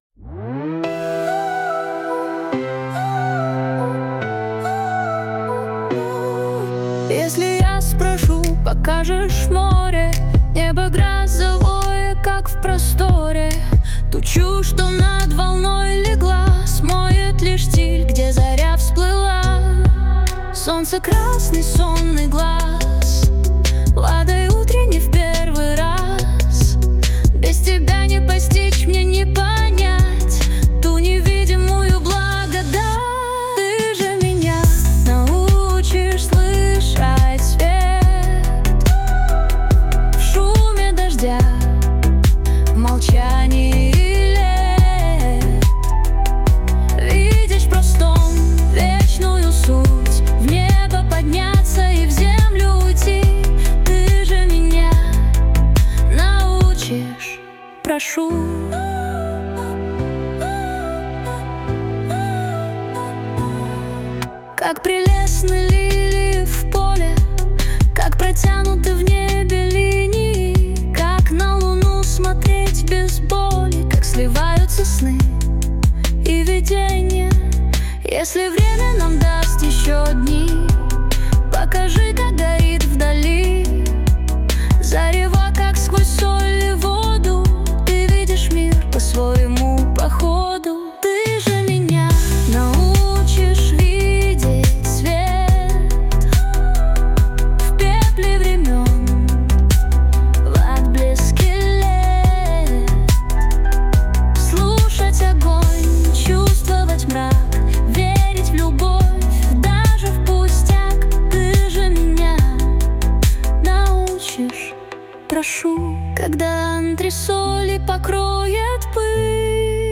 RUS, Lyric, Reggae, Funk, Rap | 22.03.2025 19:17